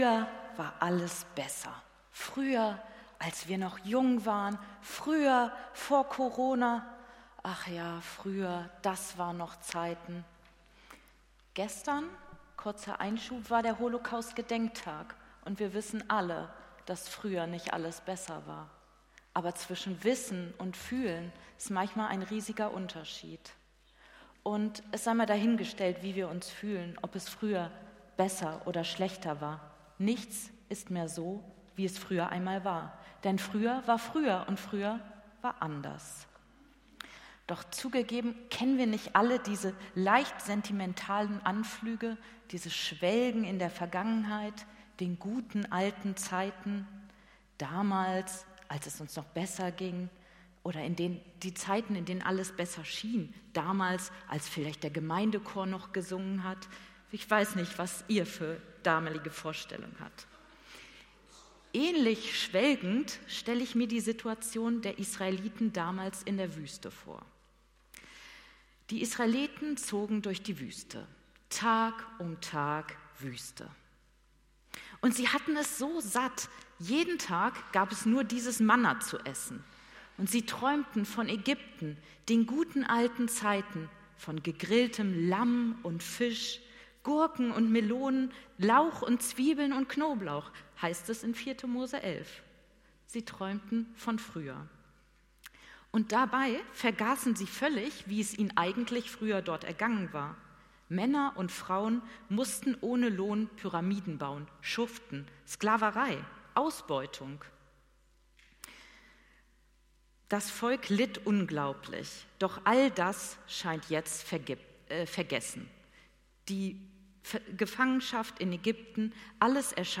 Predigt vom 28.01.2024